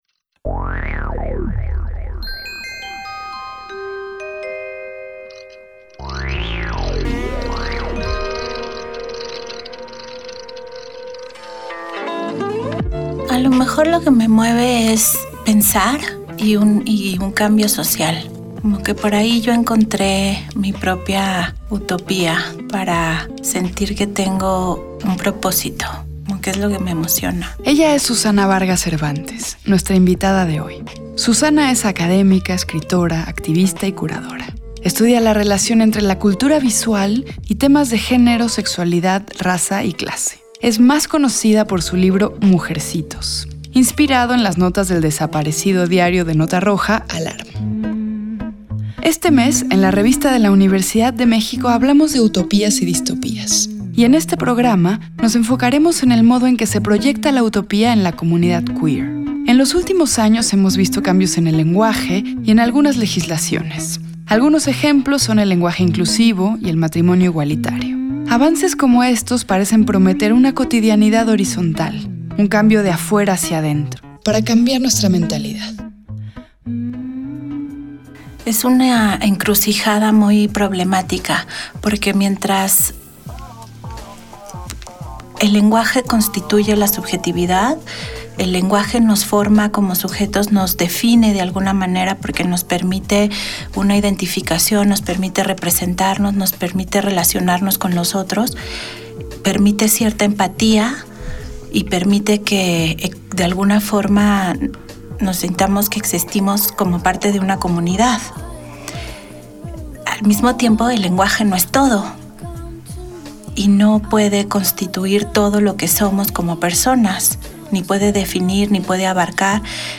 Fue transmitido el jueves 15 de noviembre de 2018 por el 96.1 FM.